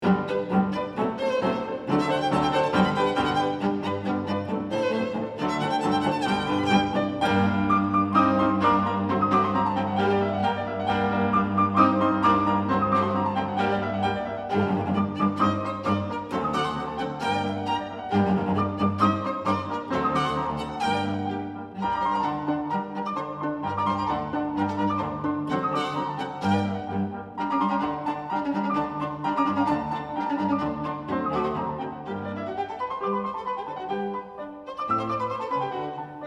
key: G-Major